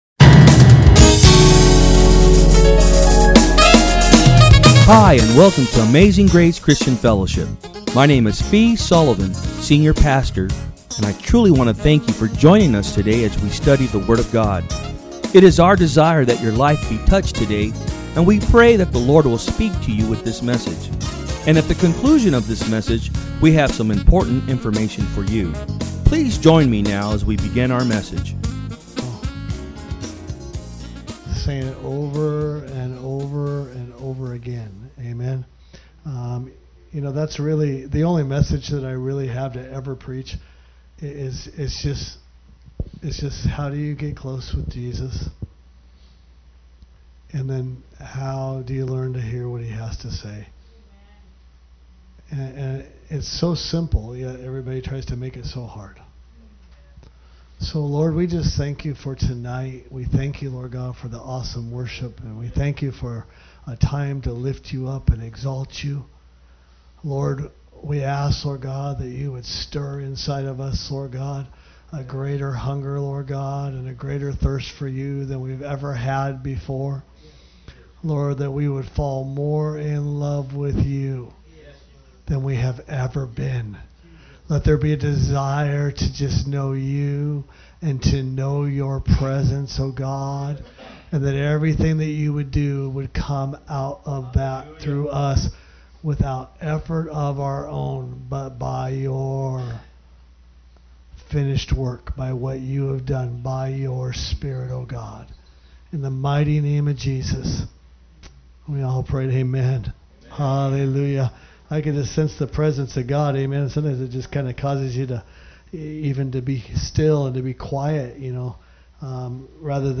From Service: "Wednesday Pm"
Tremendous Prophetic Message